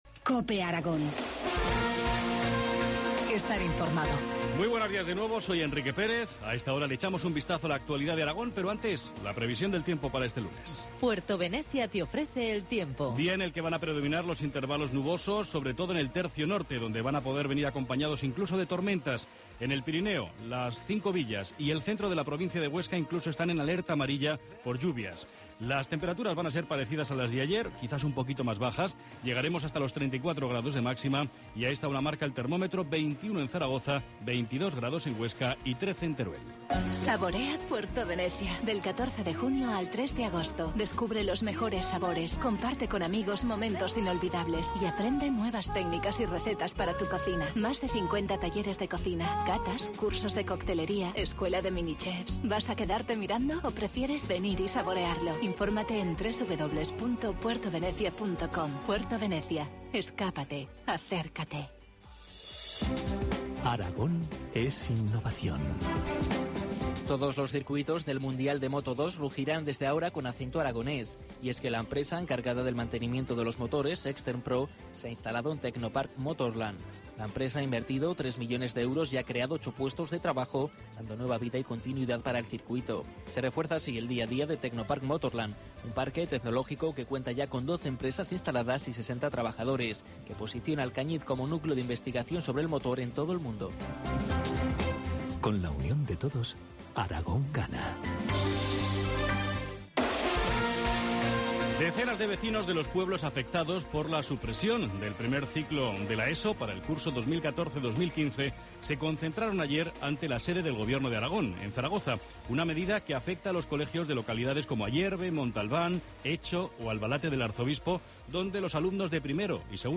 Informativo matinal, lunes 17 de junio, 8.25 horas